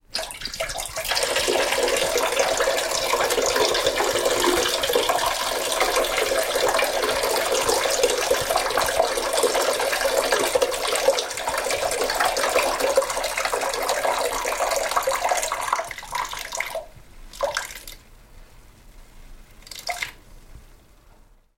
Звуки мочеиспускания
Звук: женщина писает в унитаз